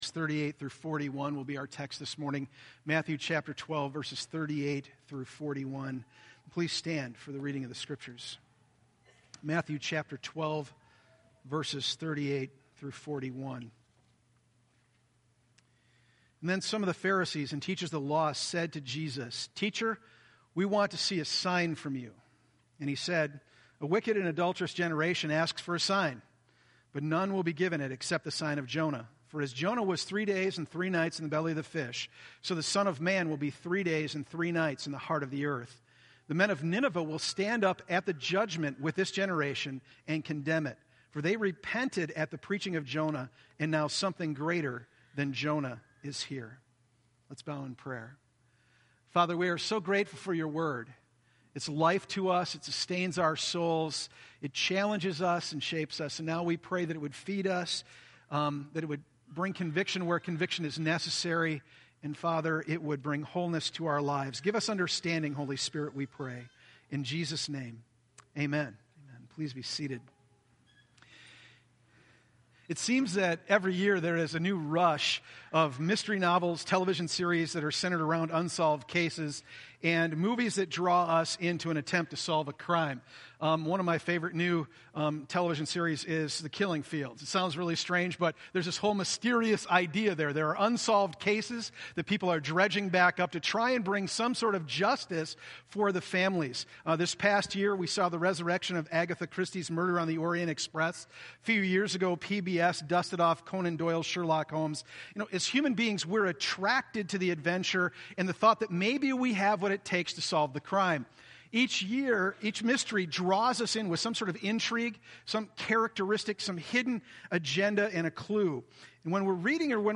Audio Sermon Notes